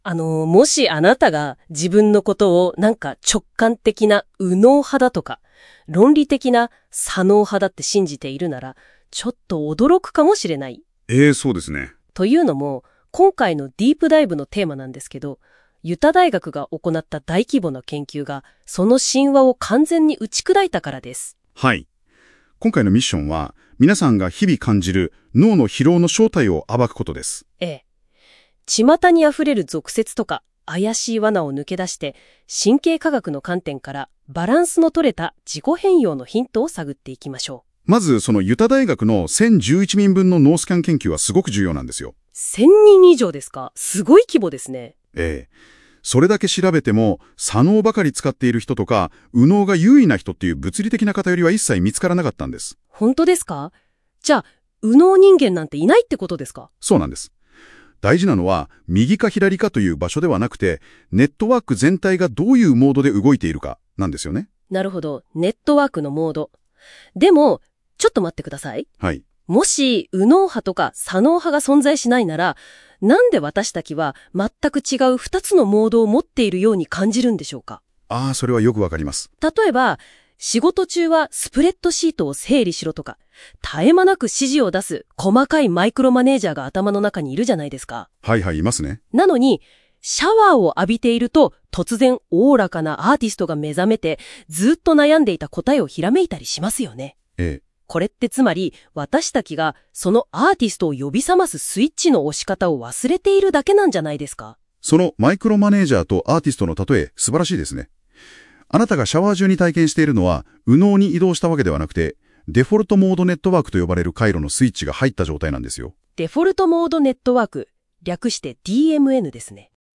音声解説を追加しました。最近は右脳覚醒ビジネスが流行していますが、やっぱり脳は脳。